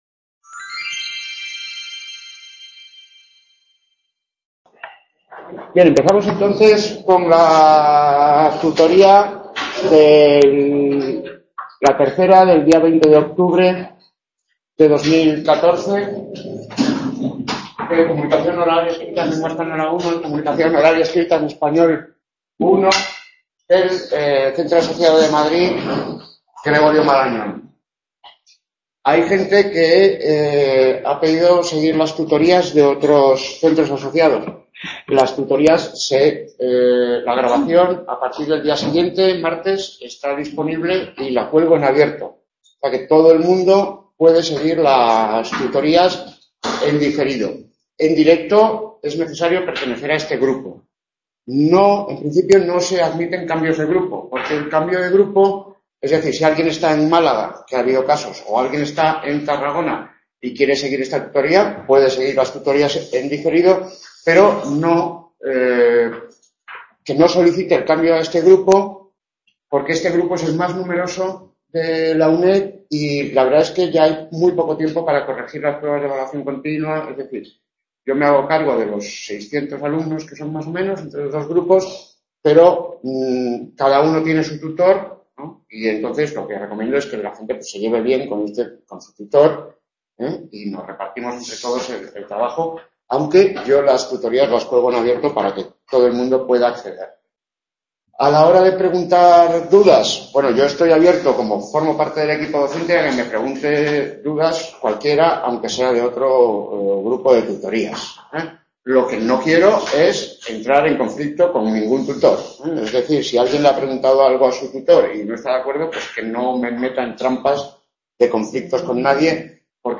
Tutorías del centro asociado de Madrid (Gregorio Marañón)